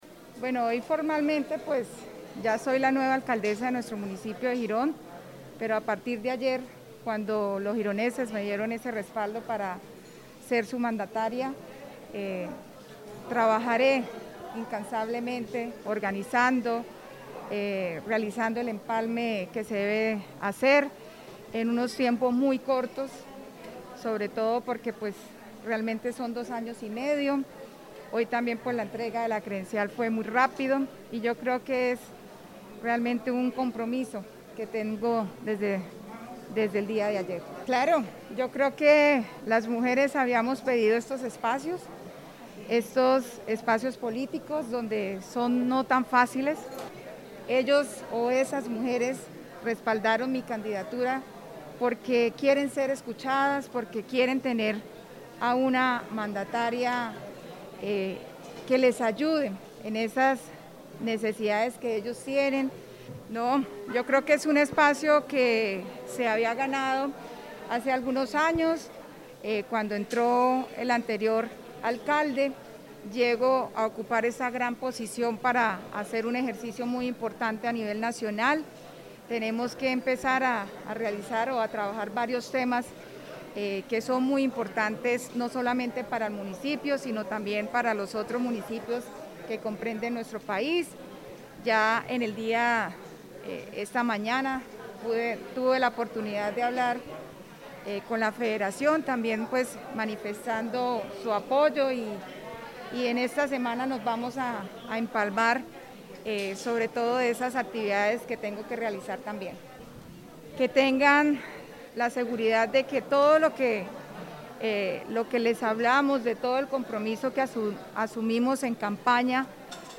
Yulia Rodríguez, Alcaldesa de Girón.mp3